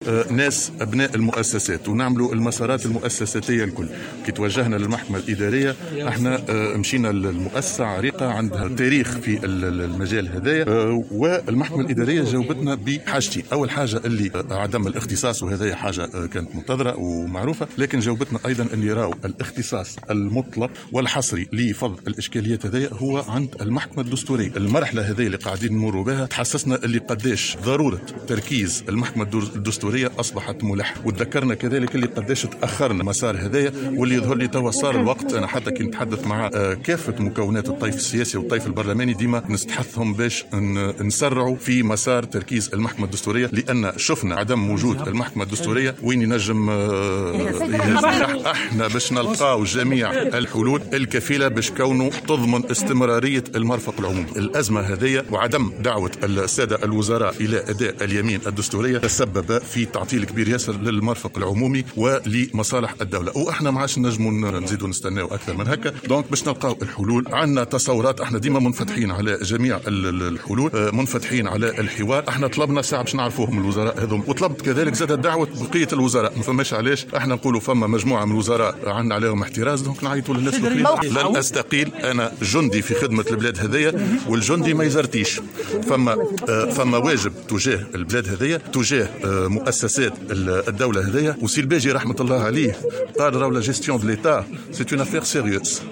أكد رئيس الحكومة هشام المشيشي في تصريح لمراسل الجوهرة "اف ام" اليوم الجمعة 12 فيفري 2021 أنه لن يستقيل من مهامه لأنه جندي في خدمة البلاد و "الجندي ما يزرطيش" و لأنه صاحب واجب تجاه الدولة وفق قوله.